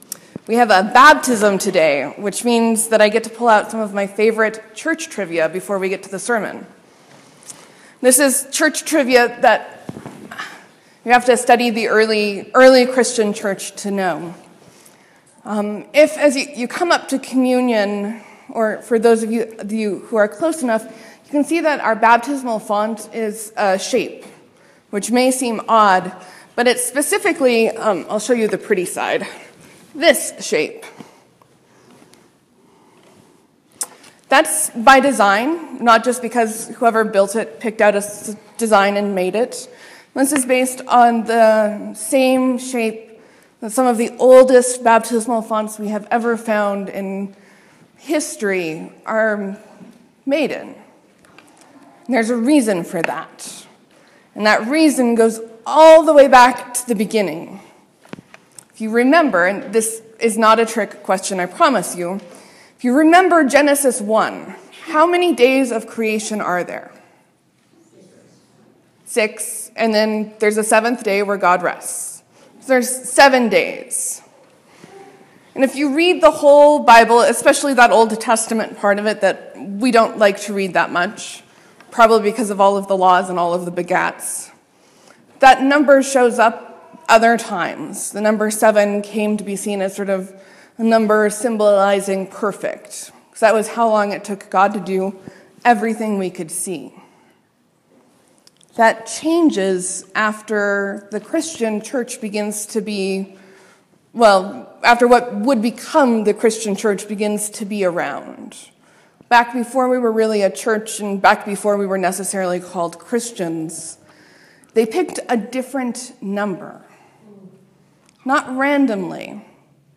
Sermon: Leviticus, the Sermon on the Mount, and the Baptismal Covenant.